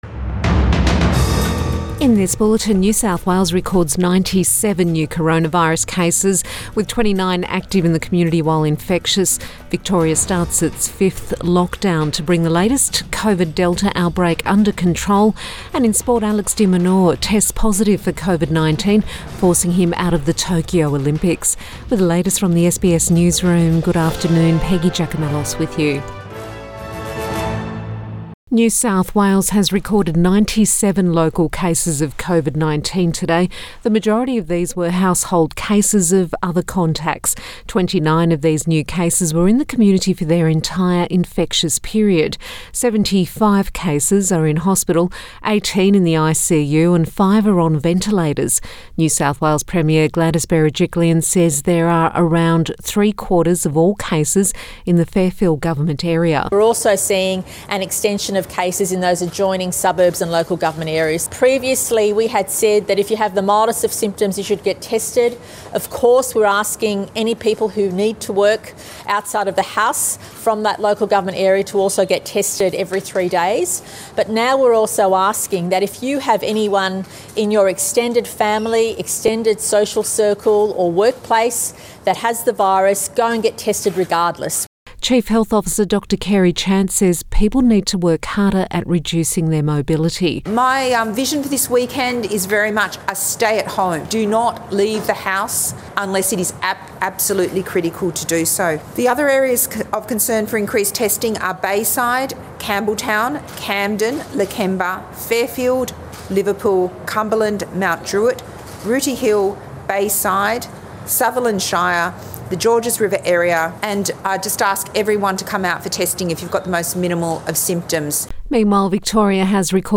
Midday bulletin 16 July 2021